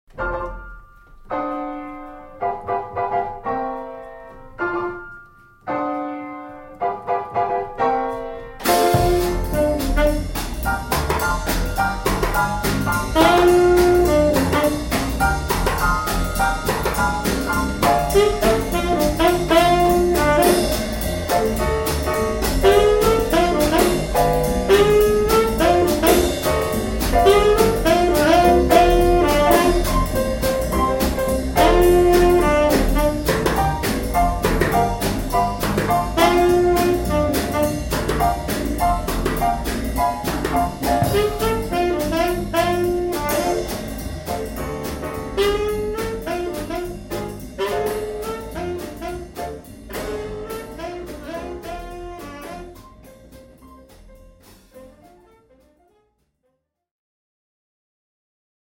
• Jazzbands